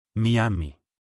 Miami[nota 1] (pronunciación en español: [maˈjami] o
Es-Miami.wav.mp3